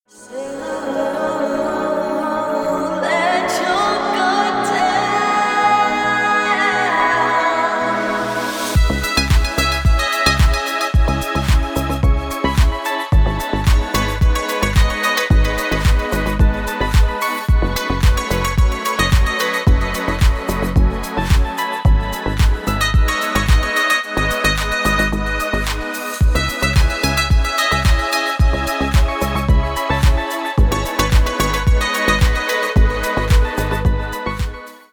Танцевальные
клубные # спокойные